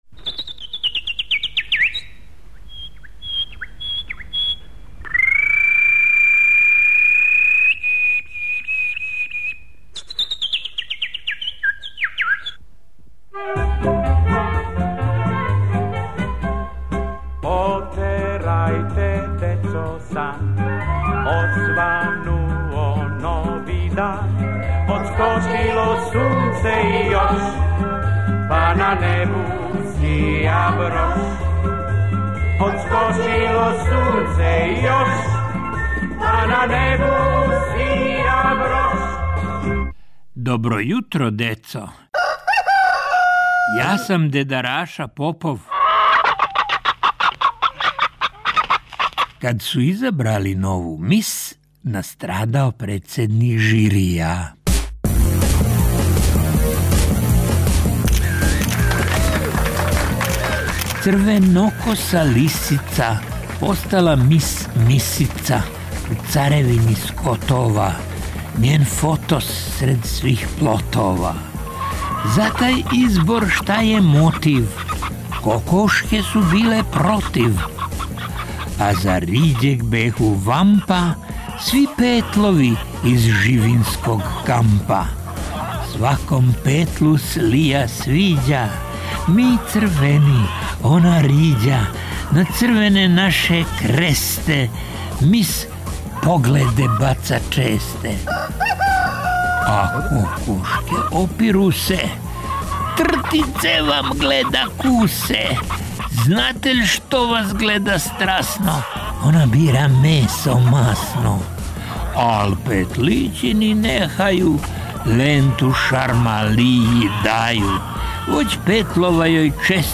Поезија, Раша Попов.